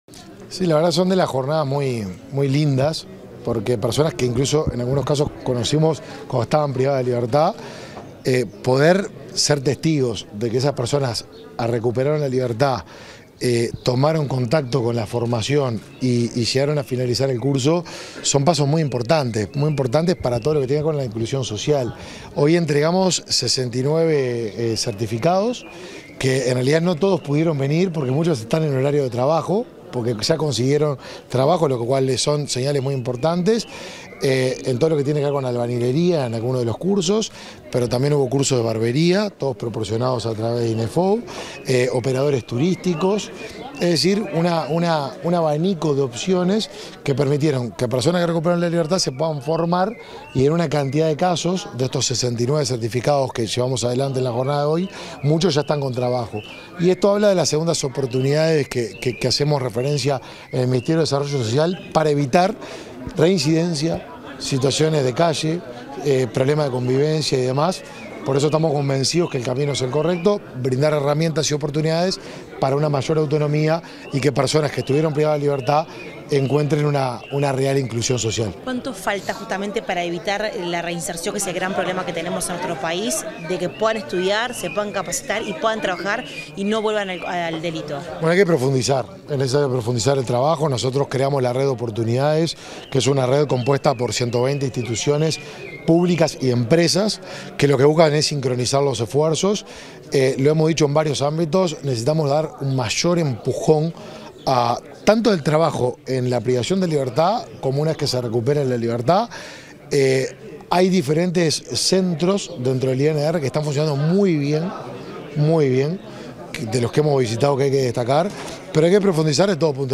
Declaraciones del ministro de Desarrollo Social, Martín Lema
Declaraciones del ministro de Desarrollo Social, Martín Lema 27/10/2023 Compartir Facebook X Copiar enlace WhatsApp LinkedIn Tras la entrega de diplomas a participantes de la Dirección Nacional de Apoyo al Liberado (Dinali) dictados por el Instituto Nacional de Empleo y Formación Profesional Profesional (Inefop), este 27 de octubre, el ministro de Desarrollo Social, Martín Lema, realizó declaraciones a la prensa.